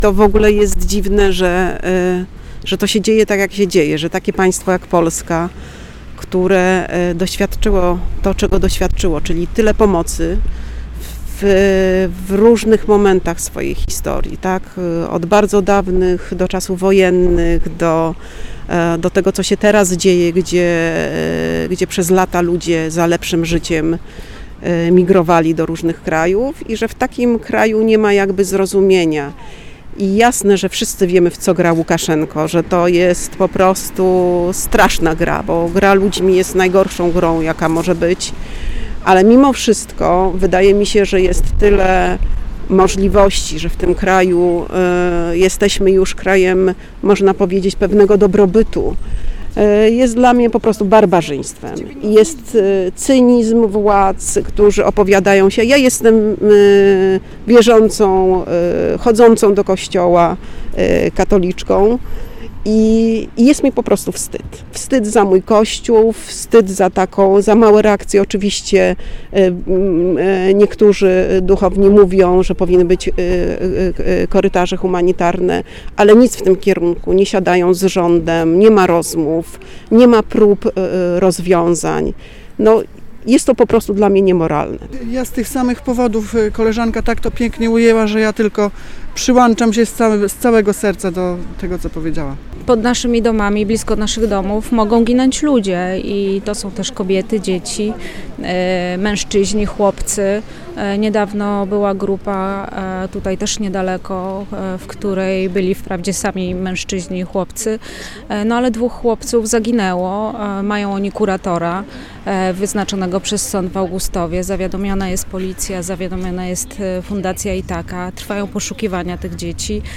Posłuchaj, co na temat sytuacji uchodźców przy granicy polsko-białoruskiej powiedziały uczestniczki protestu w Sejnach: